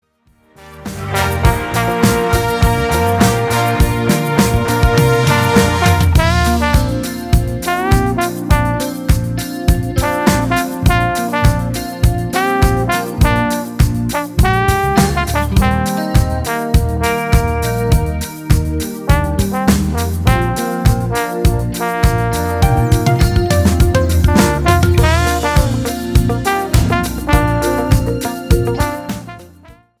POP  (3.29)